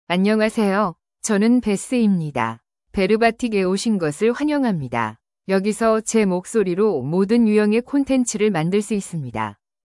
BethFemale Korean AI voice
Beth is a female AI voice for Korean (Korea).
Voice sample
Listen to Beth's female Korean voice.
Female